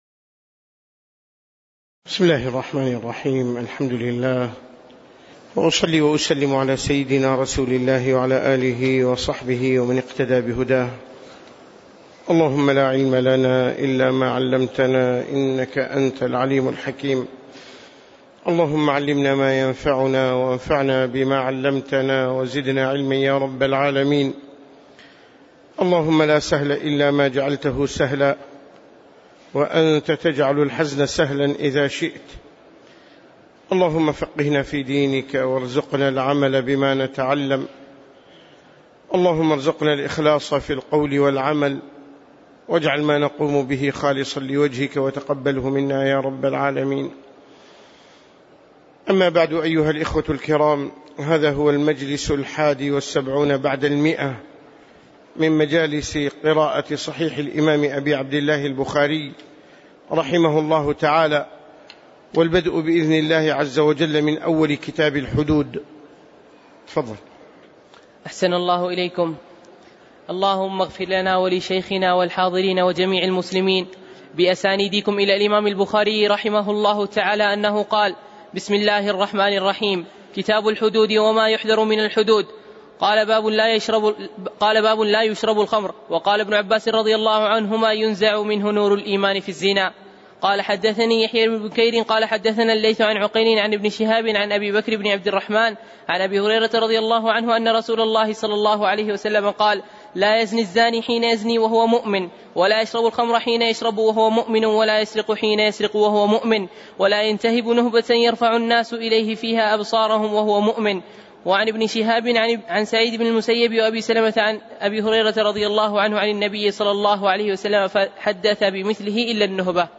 تاريخ النشر ٢٠ صفر ١٤٣٩ هـ المكان: المسجد النبوي الشيخ